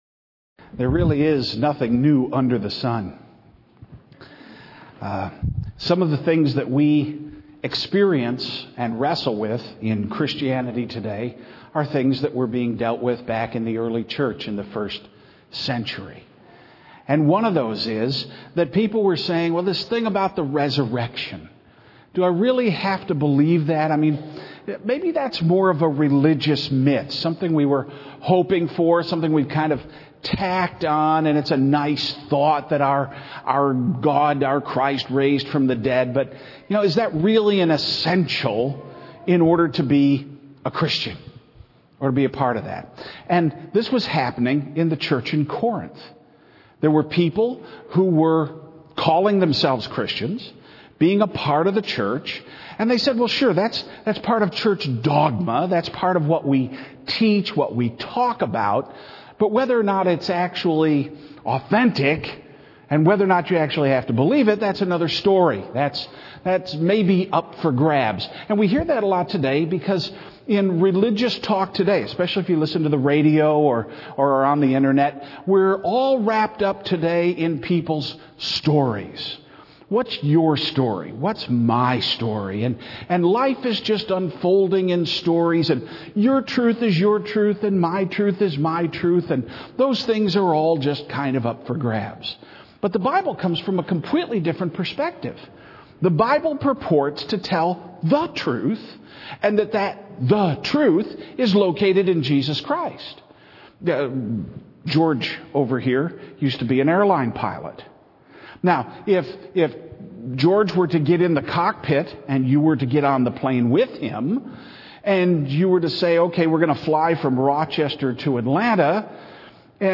Sermons | Evangelical Church of Fairport
Easter 2011: White Haven Cemetery